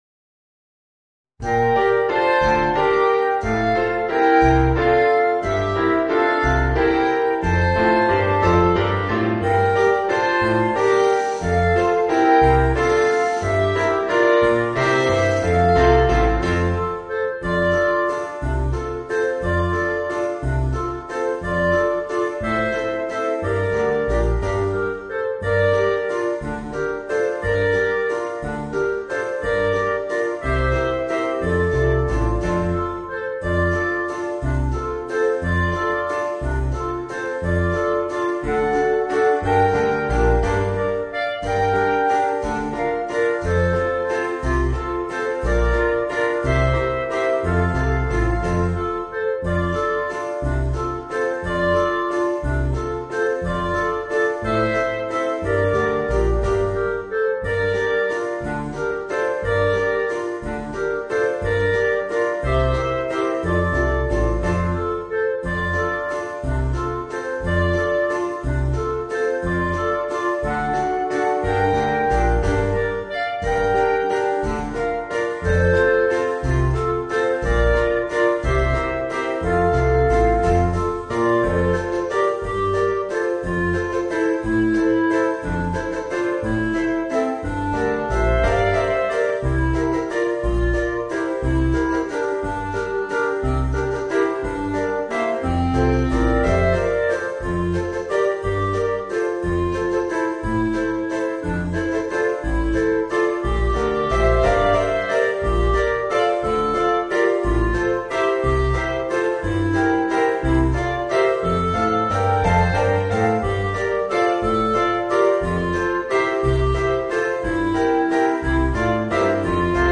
Voicing: 4 Clarinets